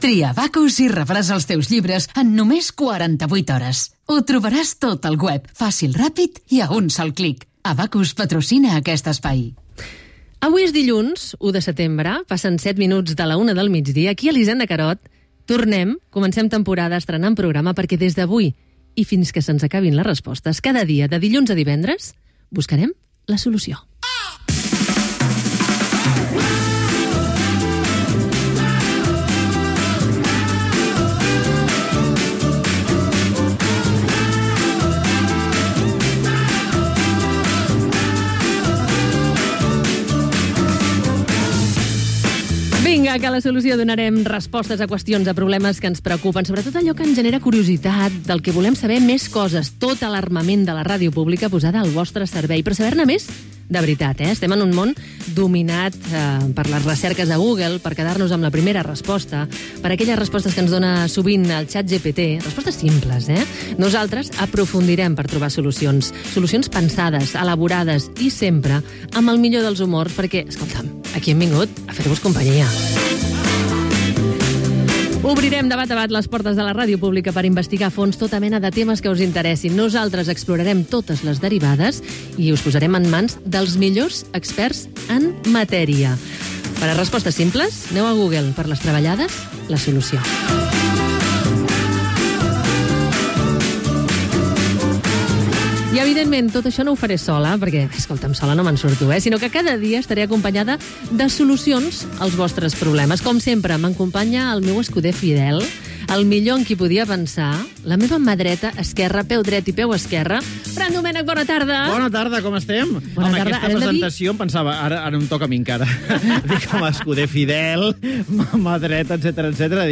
Publicitat. Inici del primer programa de la temporada 2025-2026.
Entreteniment